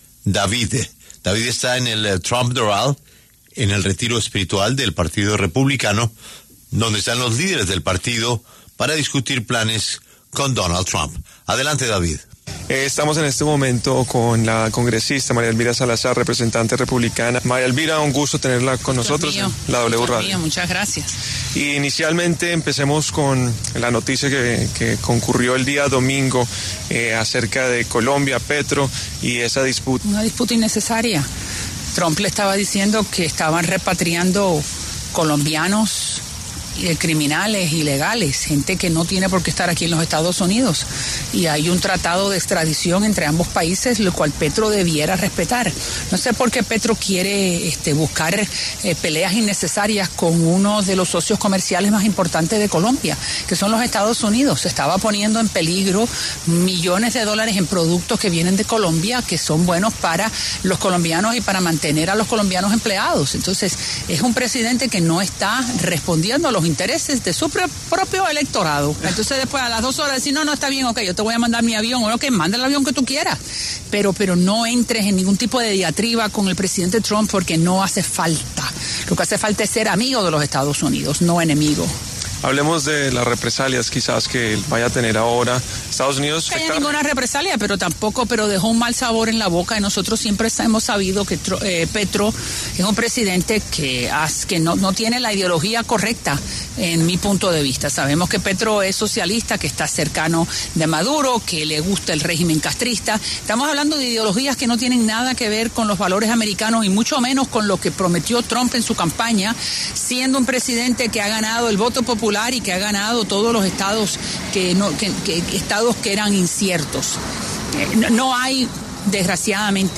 María Elvira Salazar, miembro de la Cámara de Representantes de Estados Unidos, conversó con La W a propósito de la tensión diplomática y comercial que vivió su país con Colombia luego de las determinaciones de los presidentes Gustavo Petro y Donald Trump.